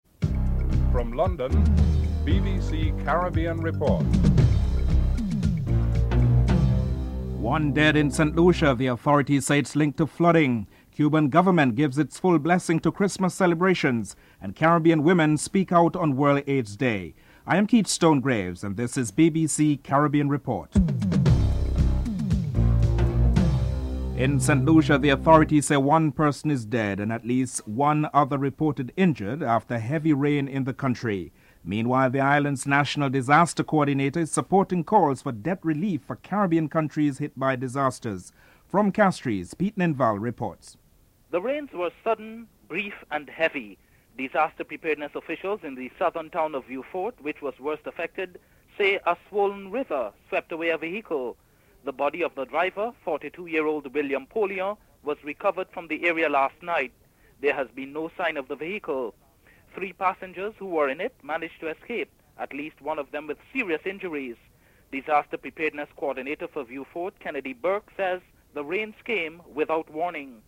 1. Headlines (00:00-00:23)